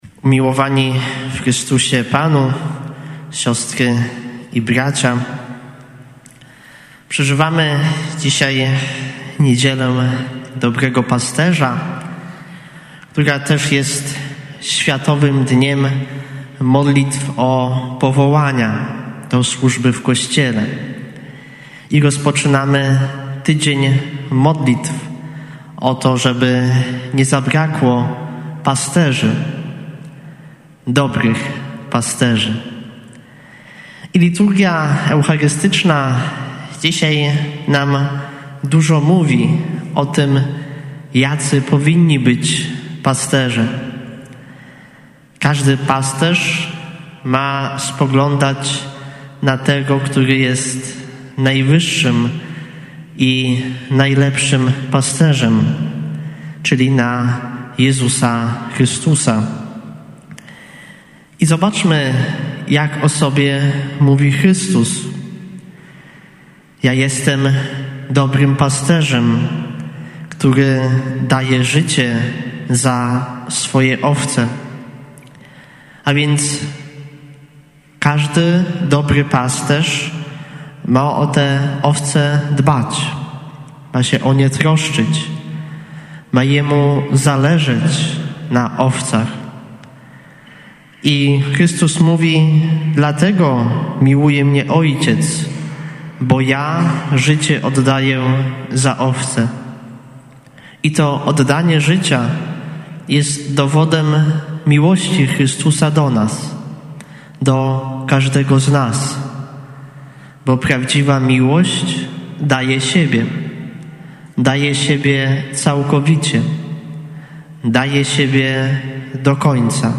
Homilia